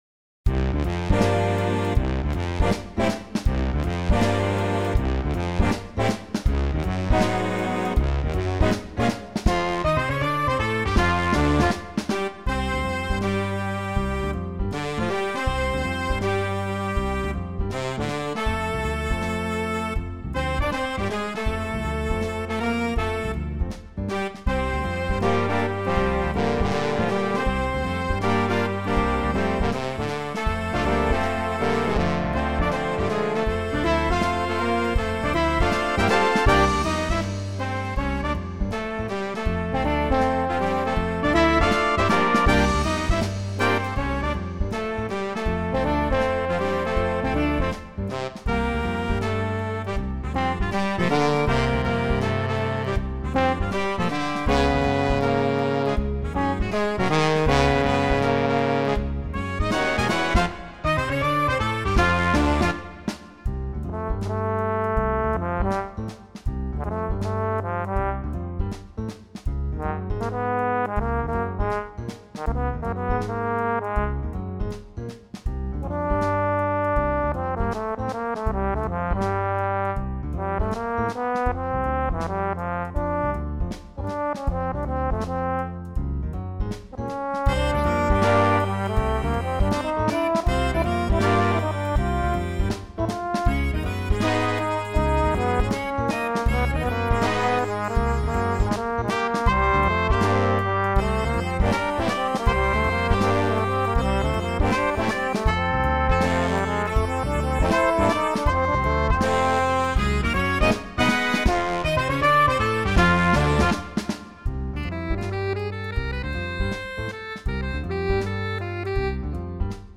This page lists many of my original charts (compositions and arrangements) for big band.
In these cases, I generated MIDI-based recordings using Dorico and some nice sample libraries. I then used an audio editor to add solos (which I played from a keyboard) and piano comping as needed.